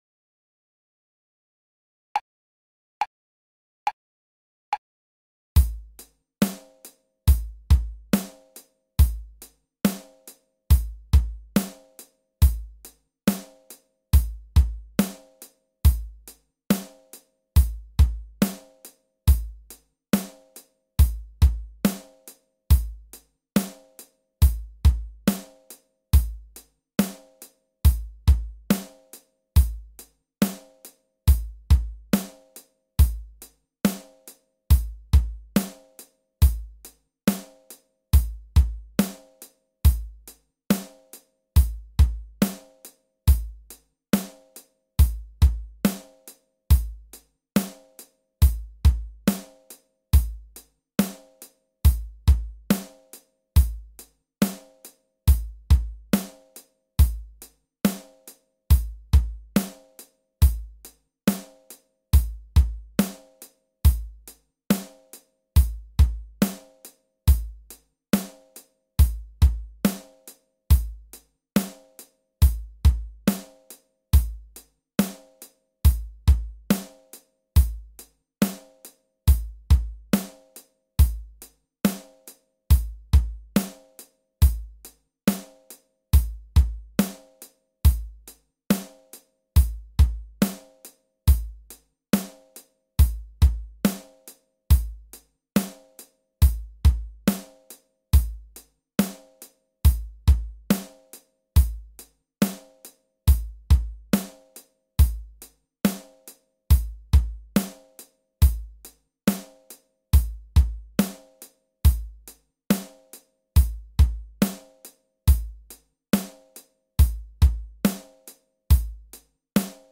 BPM Rhythm Track
Sound : Drum 8Beat